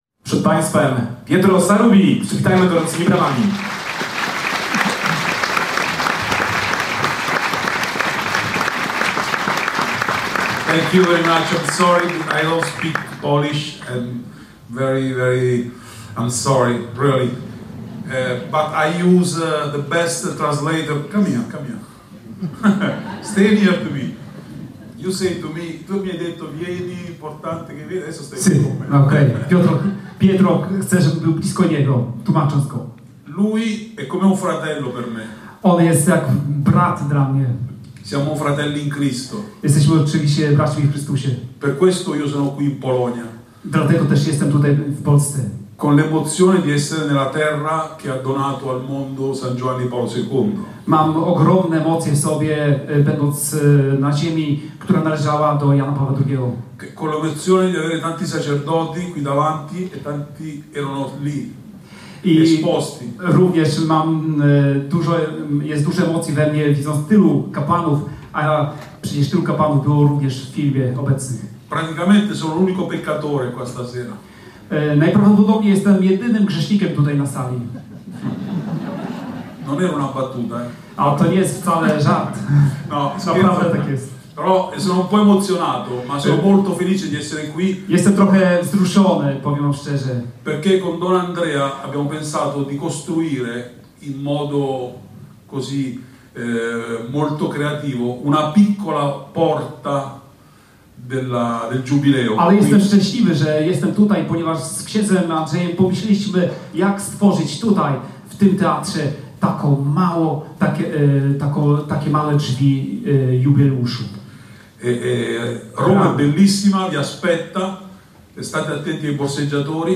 W auli Szkół Katolickich w piątkowy (17.01) wieczór odbyło się spotkanie pod tytułem ,,Nawrócenie – czy to jest naprawdę możliwe?”, organizowane przez Instytut Trójcy Świętej Ruchu Gloriosa Trinita.